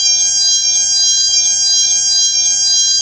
fgdata/Aircraft/c172p/Sounds/stall2.wav at 054da4e69170e51c56b3fe1d199cb7d26356d2c6
stall2.wav